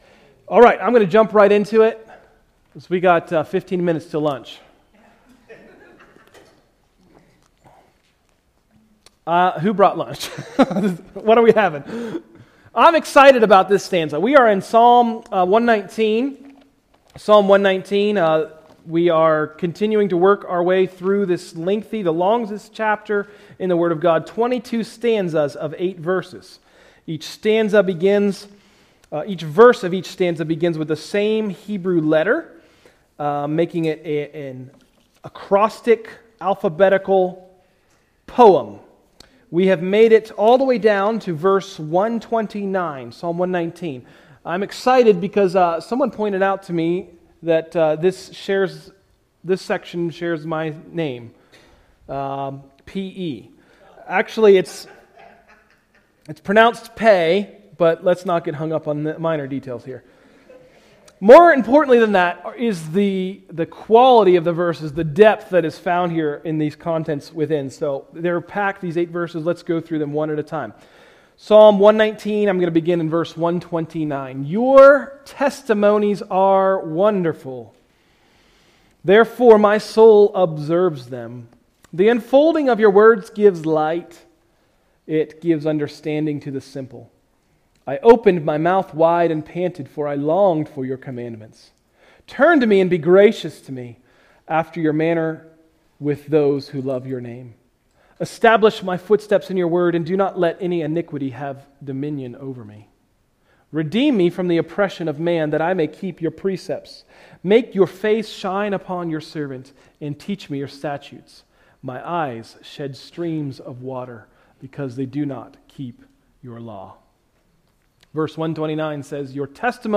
In this teaching we are exhorted to pray for the favor of God over us as we stand on God's Word.